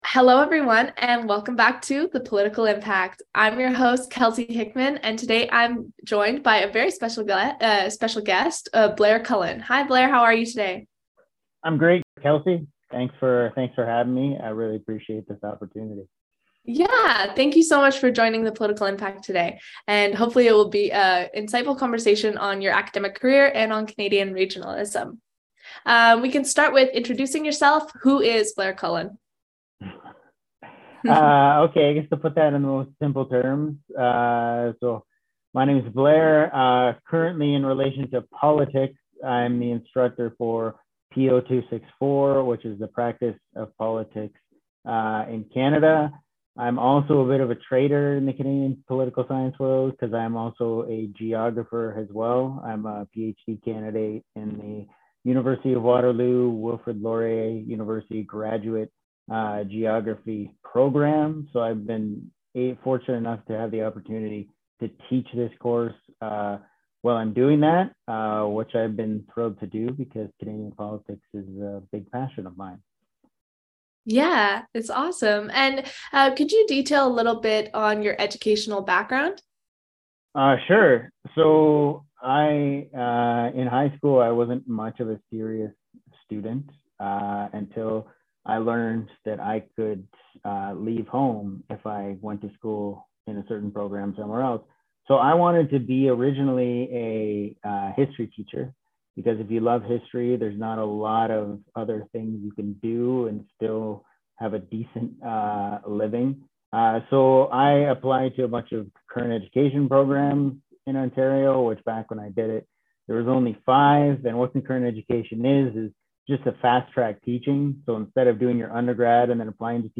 The Political Impact - Interview